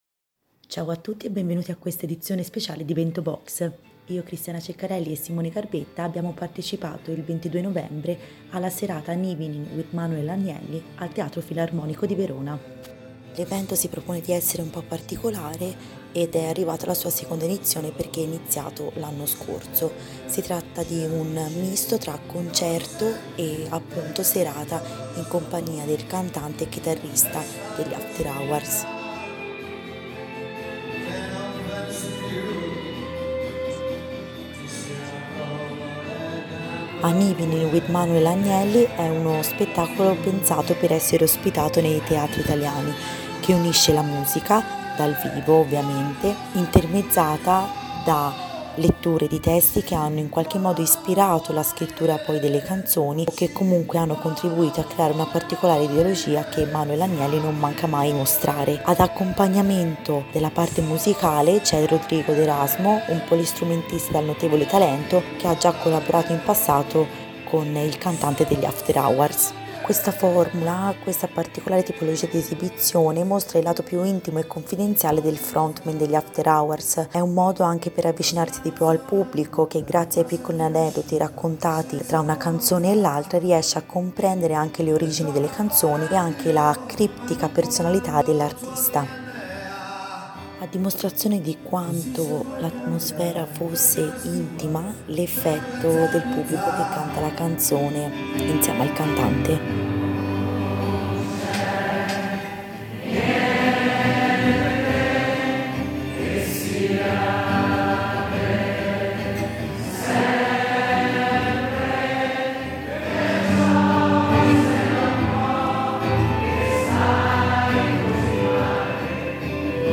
Manuel Agnelli, accompagnato dal poli strumentista Rodrigo d’Erasmo, ha dato vita a uno spettacolo eclettico, fatto di musica, lettura e dialogo con il pubblico.